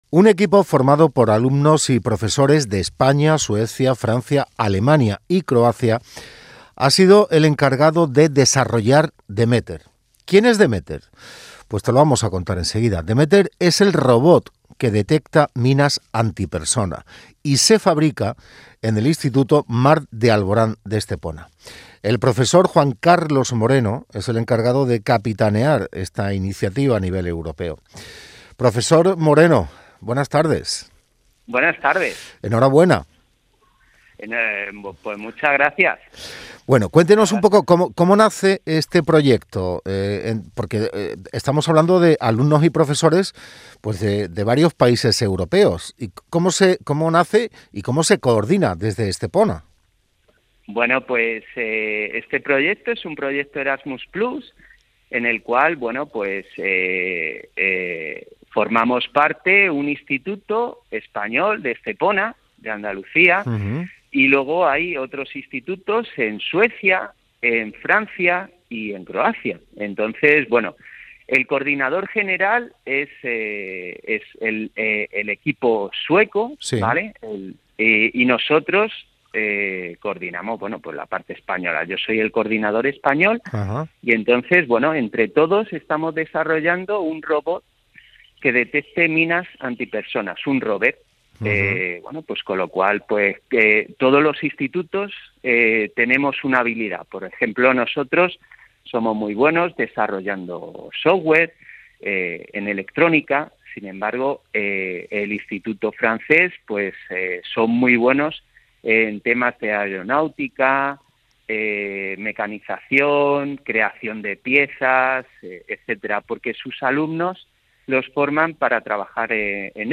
Radio interview on Canal Sur Radio.